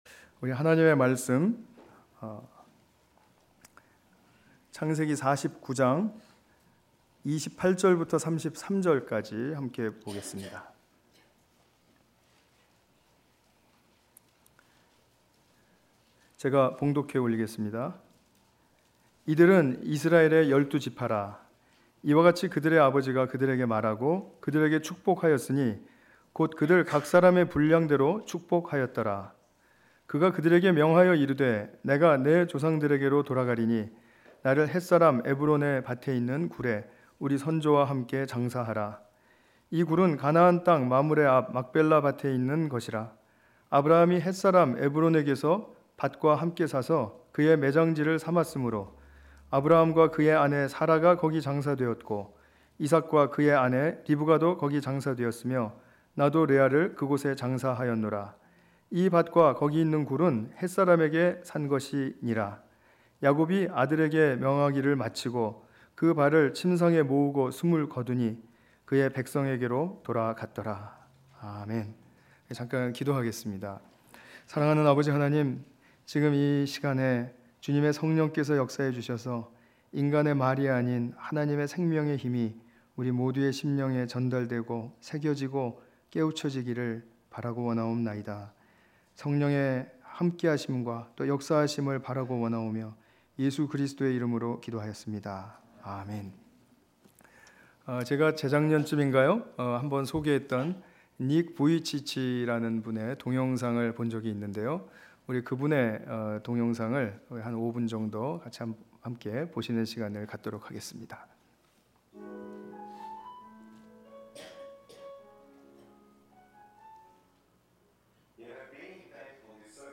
주일예배